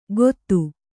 ♪ gottu